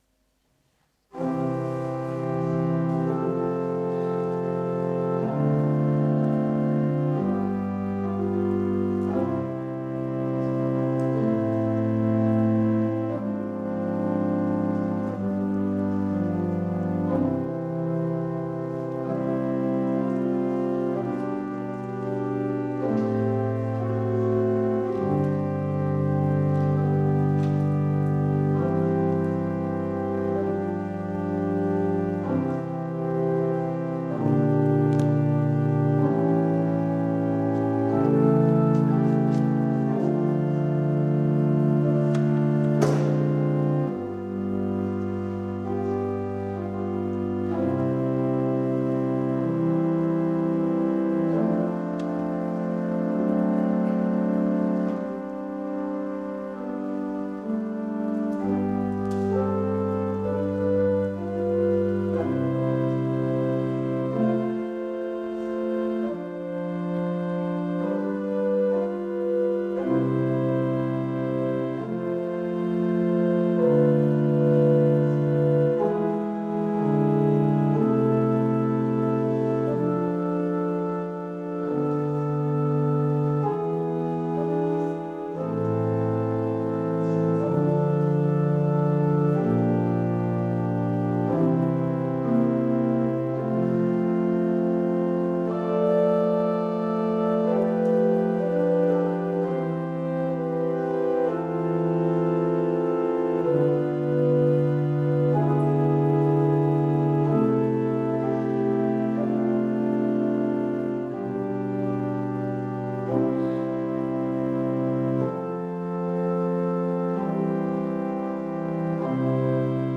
Gottesdienst am 19. Oktober